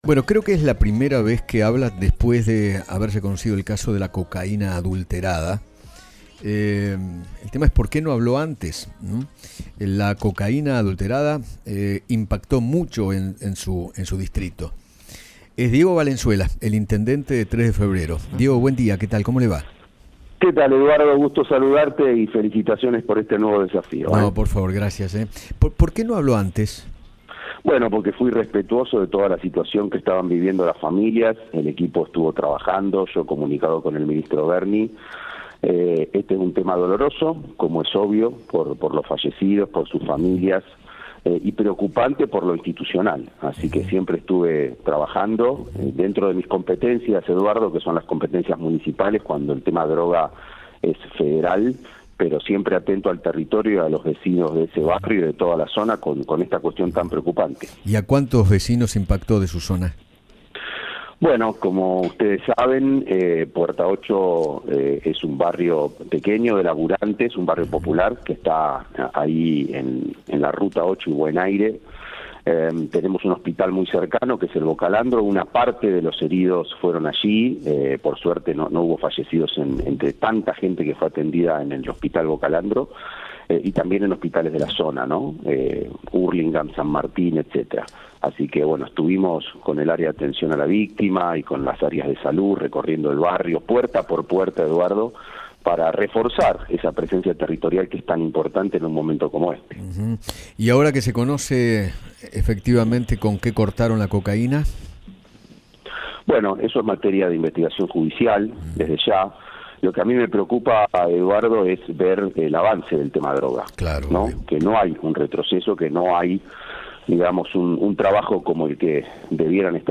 Diego Valenzuela, intendente de Tres de Febrero, habló con Eduardo Feinmann sobre lo ocurrido en “Puerta 8″ con la cocaína adulterada e hizo referencia a su silencio público durante el caos.